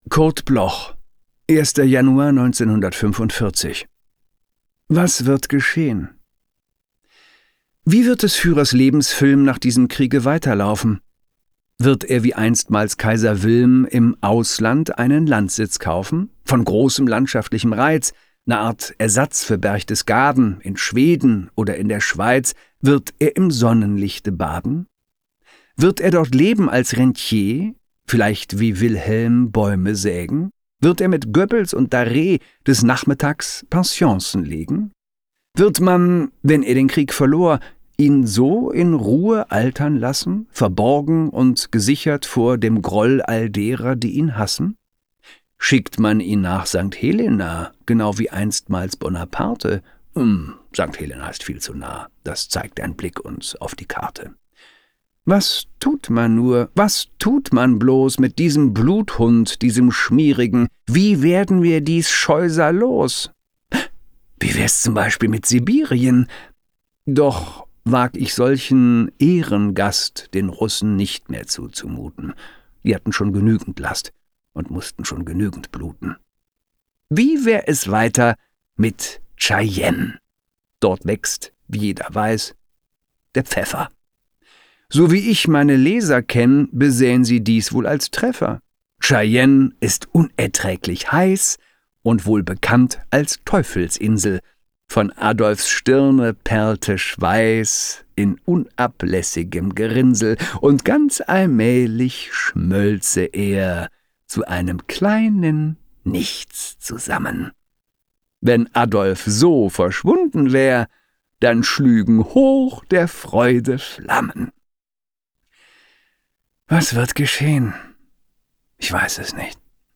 Christoph Maria Herbst (* 1966) is een Duits acteur en stemartiest.